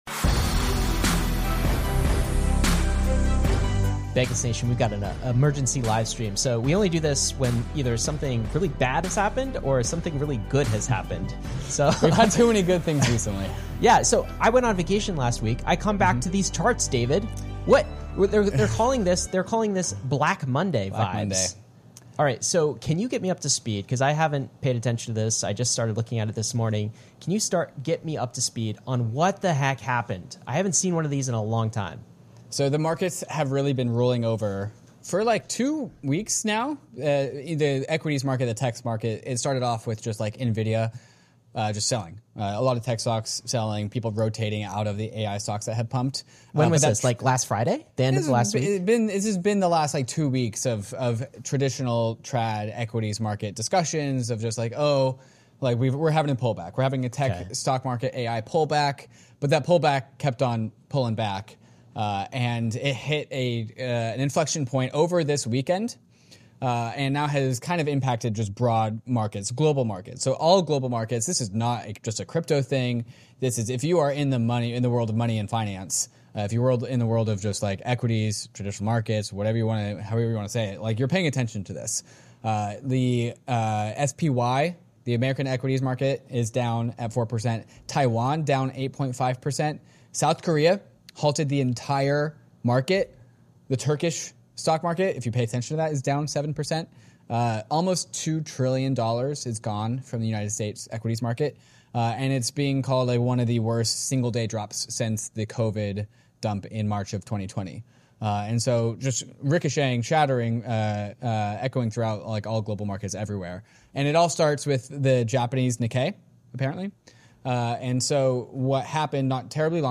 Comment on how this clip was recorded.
We break it all down live on stream. What just happened, what does it mean, and where we go from here.…